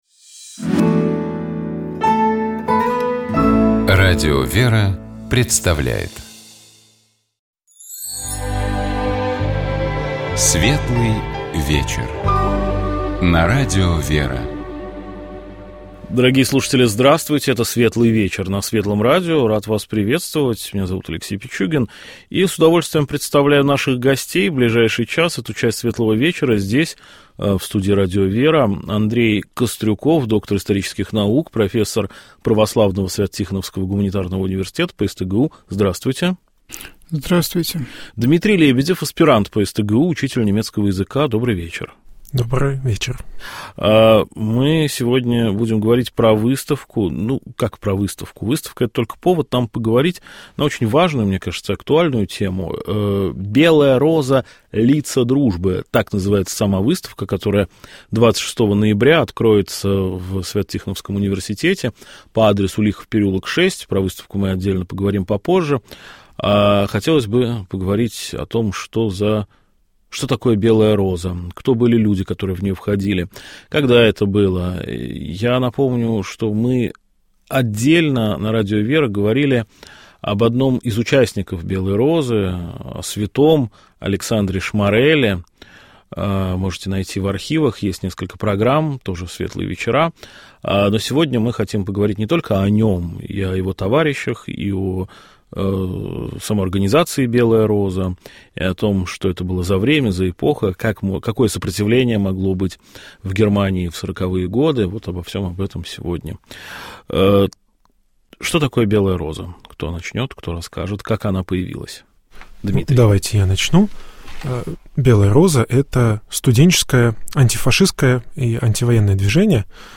На литургии в Великий Четверг — в день, посвящённый воспоминаниям о Тайной Вечере, последней трапезе Христа с учениками, — хор поёт песнопение под названием «Странствия Владычня».
Задостойником песнопение называется потому, что его исполняют вместо молитвы «Достойно есть», посвящённой Пресвятой Богородице. Давайте попробуем разобраться, о чём это песнопение, и послушаем его в исполнении сестёр Орского Иверского женского монастыря.